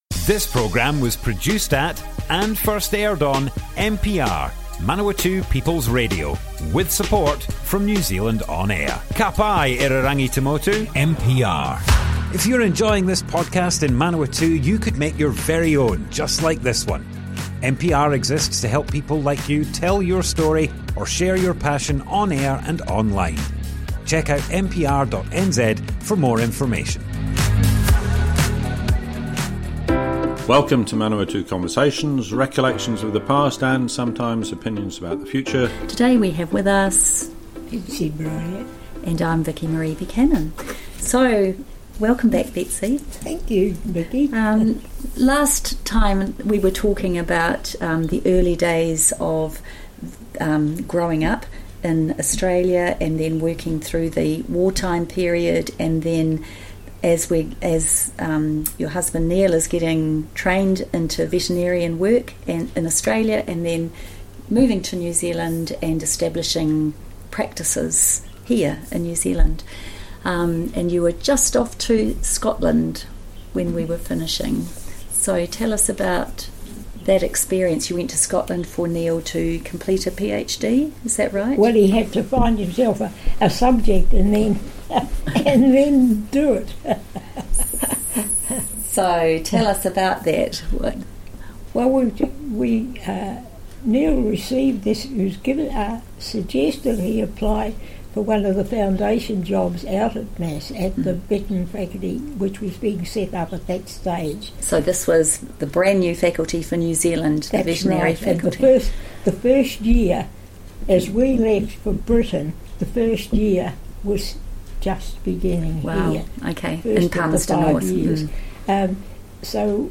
Manawatu Conversations More Info → Description Broadcast on Manawatu People's Radio, 23rd September 2025.
oral history